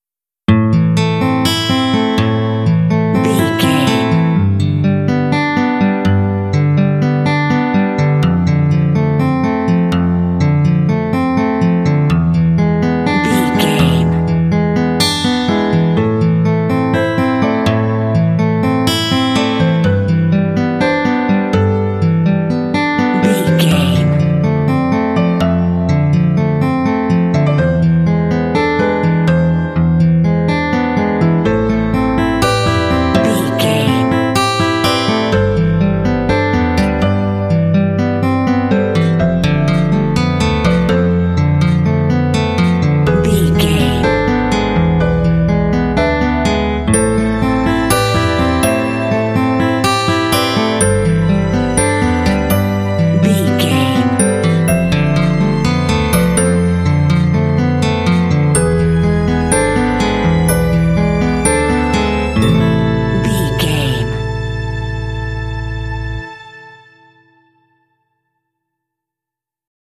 Aeolian/Minor
Slow
serene
dreamy
acoustic guitar
strings
cinematic